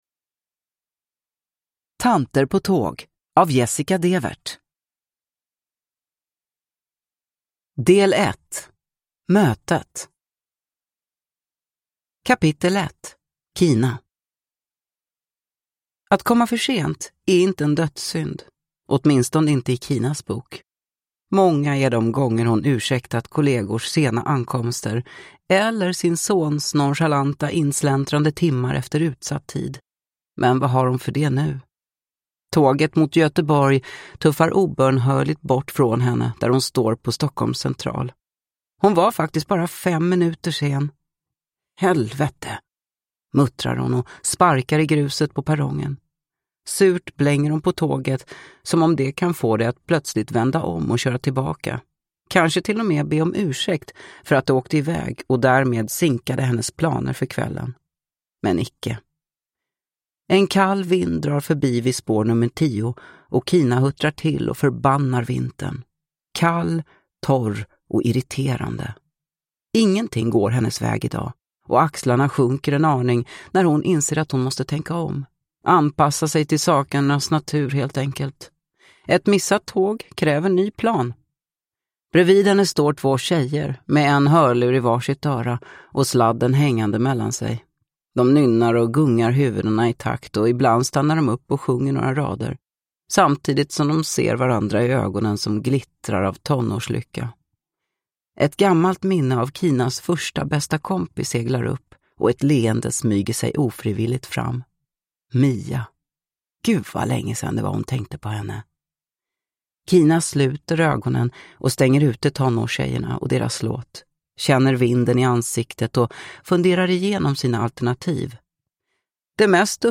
Tanter på tåg – Ljudbok